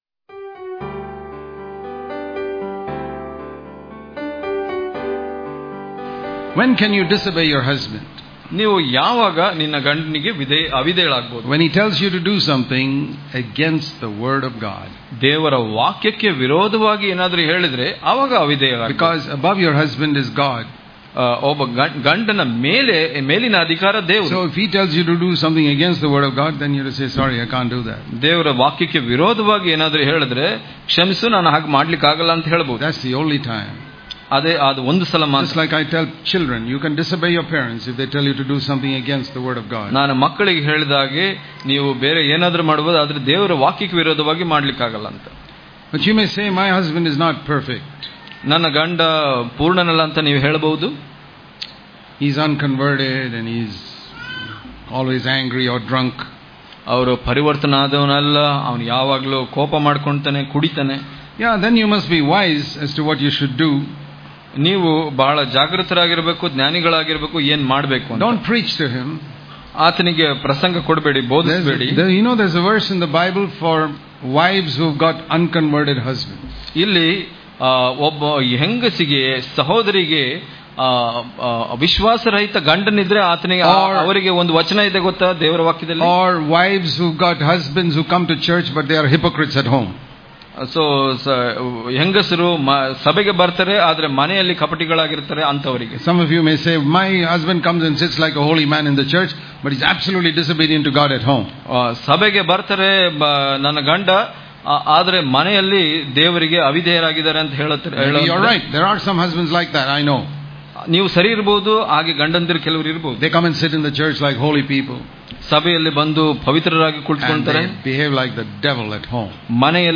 April 13 | Kannada Daily Devotion | Our Behavior Is More Important Than Preaching In The Home Daily Devotions